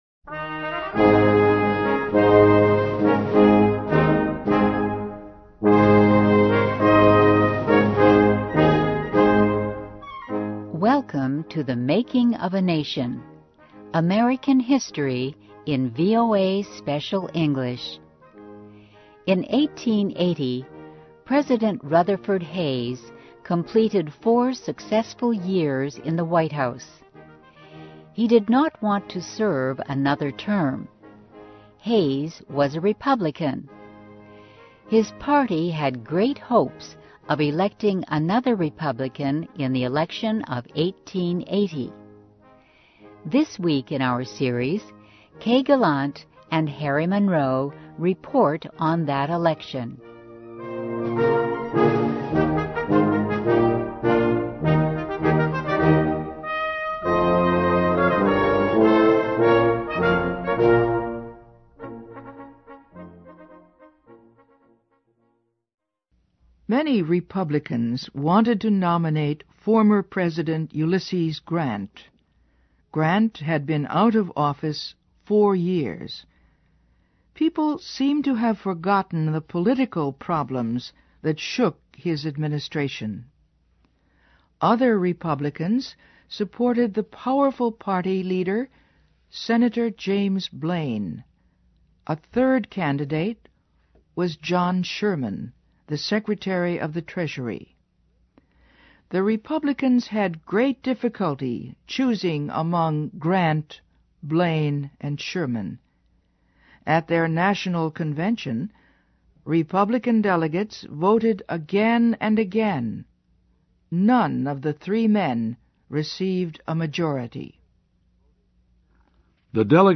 Welcome to THE MAKING OF A NATION �C American history in VOA Special English.